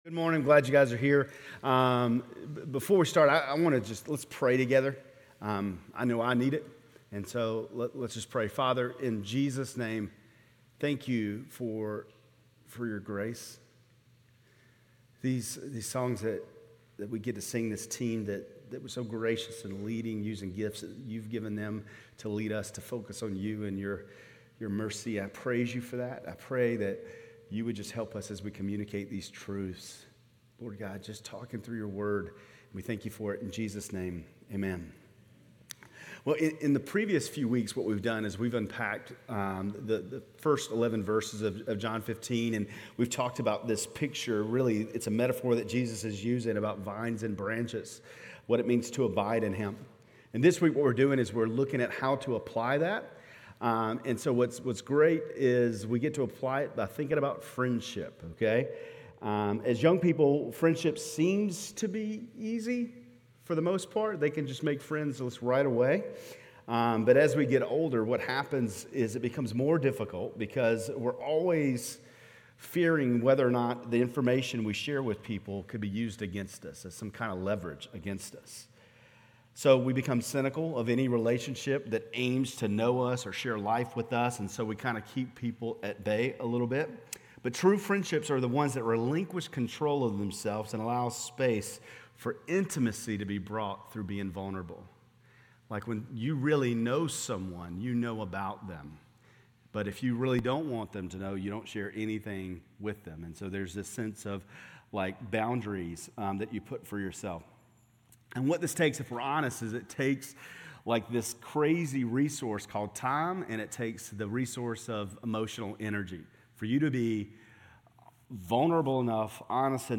Grace Community Church Lindale Campus Sermons Life in the Vineyard: John 15:12-15 Feb 05 2024 | 00:22:05 1x 00:00 / 00:22:05 Subscribe Share RSS Feed Share Link Embed